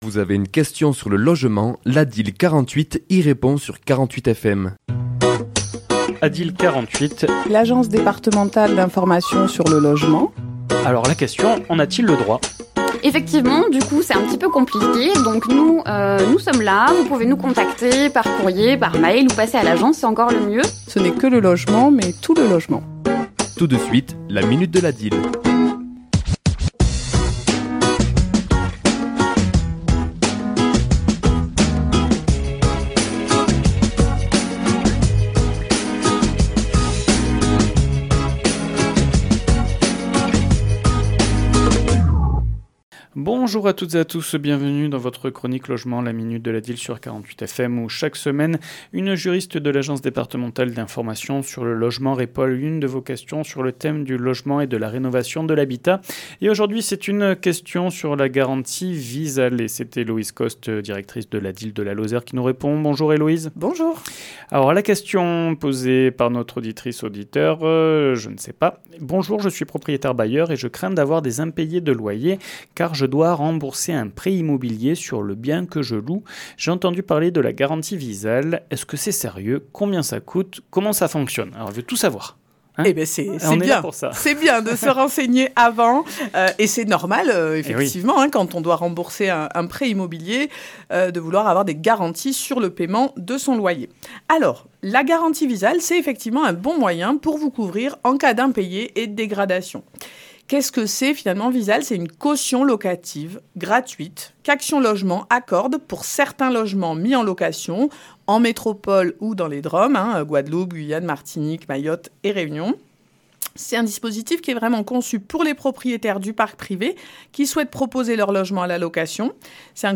Chronique diffusée le mardi 04 mars à 11h et 17h10